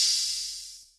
pbs - power-c [ OpHat ].wav